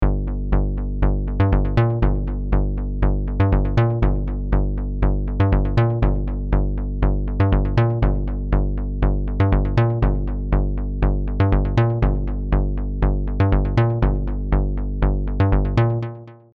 We’ll begin by putting together our sequence, which consists of three parts, each played with U-he’s Diva synth.
We’ve programmed the MIDI notes below, in the key of G minor, playing over eight bars: